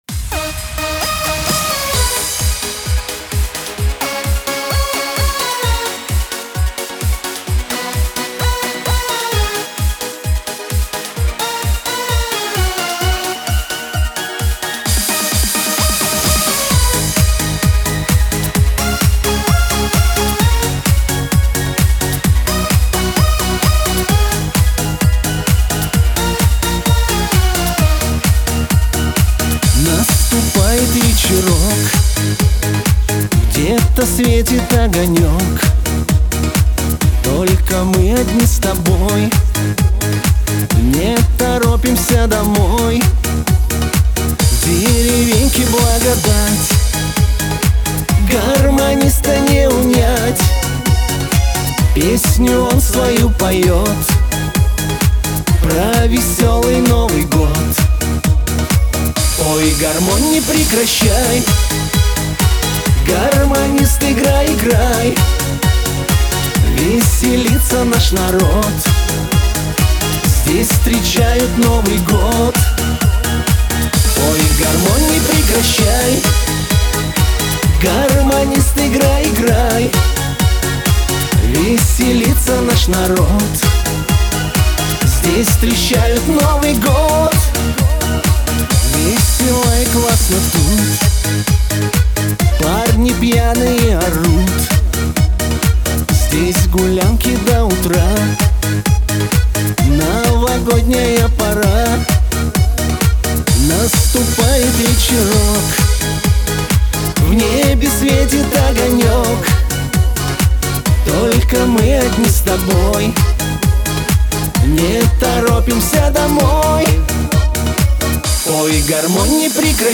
Лирика , диско
pop